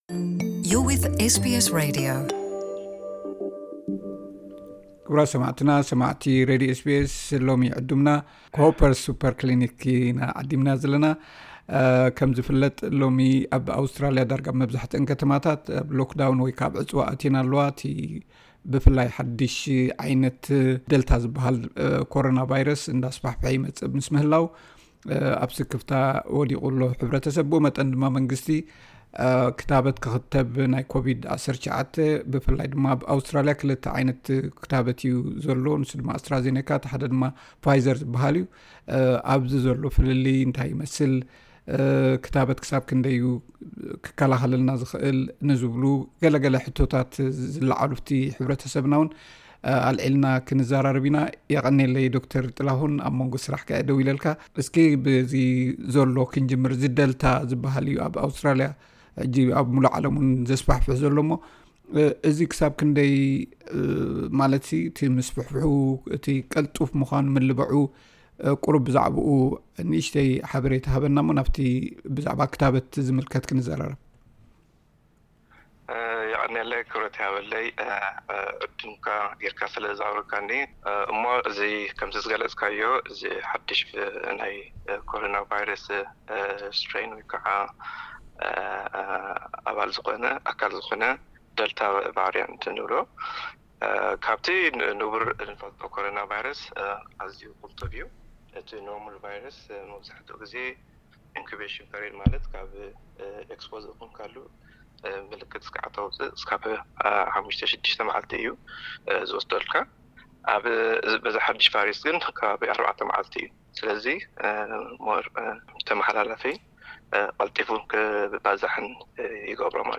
ዝርርብ ብዛዕባ ክታበት ኮሮናን ስክፍታታት ሕብረተሰብናን